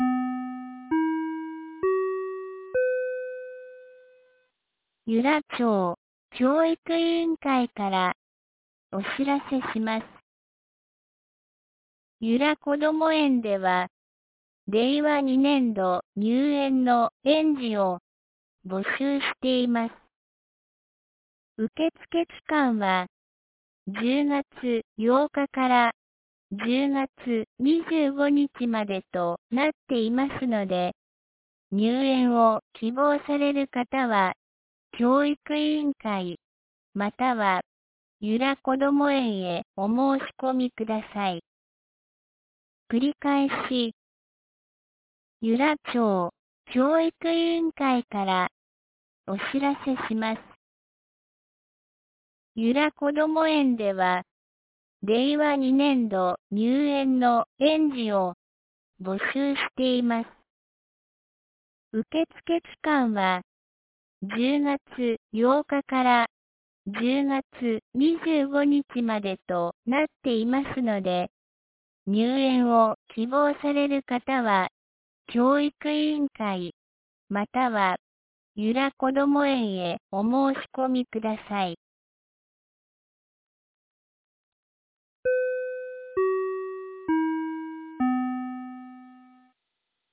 2019年10月08日 17時12分に、由良町から全地区へ放送がありました。